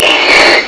snd_25212_Slurp.wav